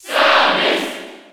Category:Crowd cheers (SSB4) You cannot overwrite this file.
Samus_Cheer_French_NTSC_SSB4.ogg